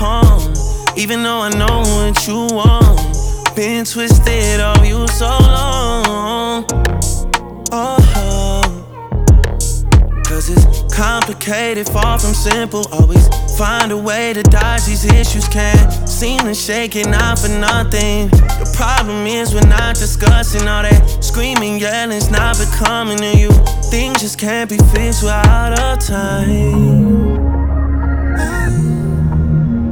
• R&B/Soul